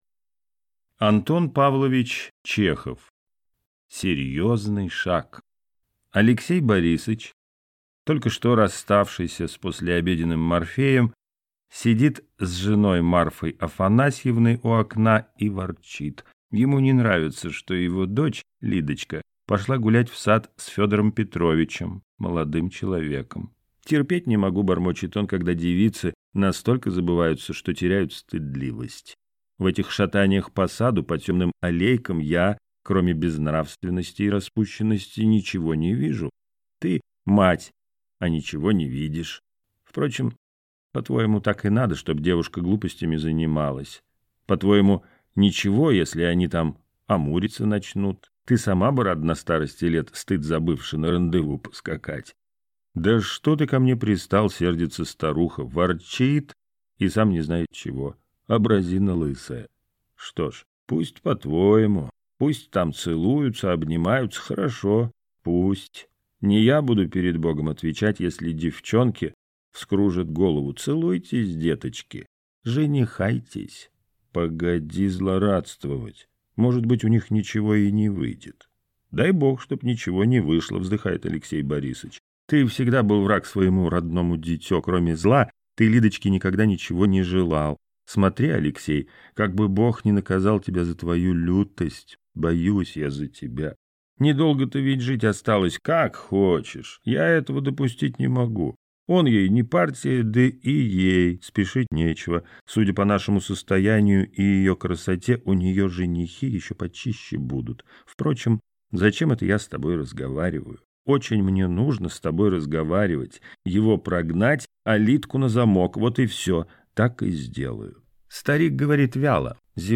Аудиокнига Серьезный шаг | Библиотека аудиокниг